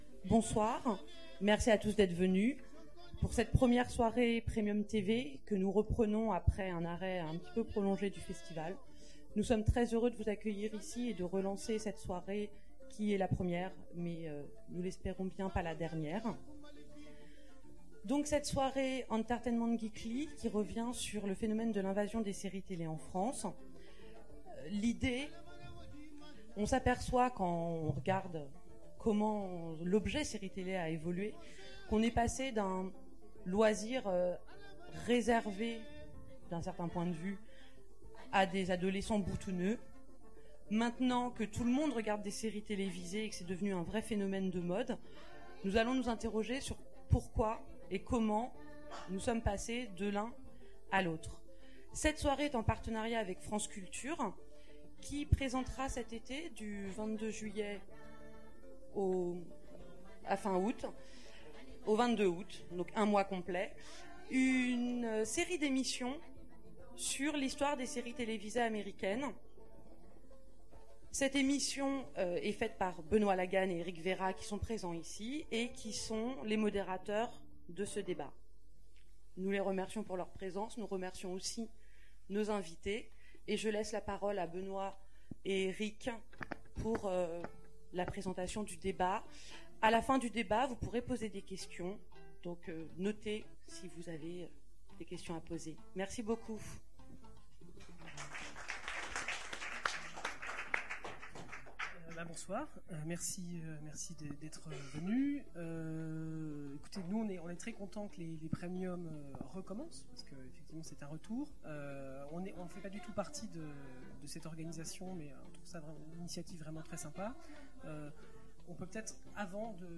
Revivez la soirée Entertainment Geekly, l’invasion des séries télévisées en France en écoutant le podcast complet du débat du 3 Juillet 2008 (2h09min).